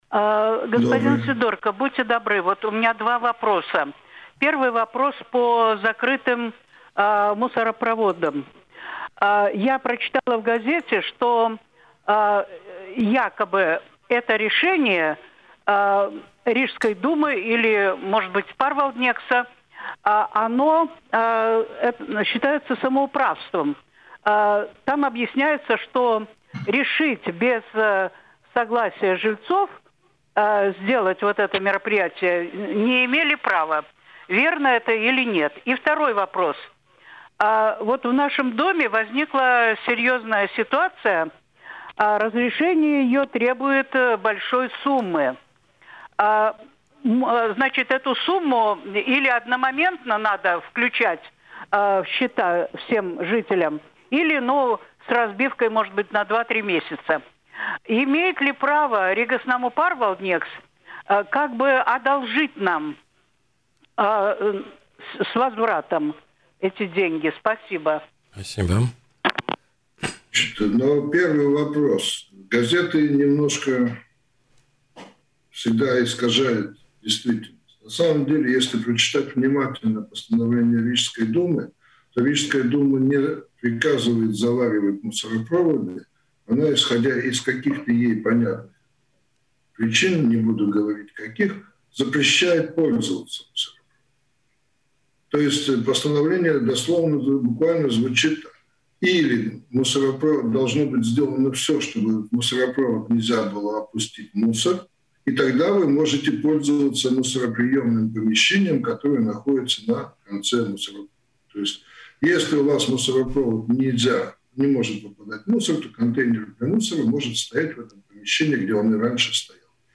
Напомним, программа «Добро пожаловаться» выходит на радио Baltkom каждый понедельник в 10:00.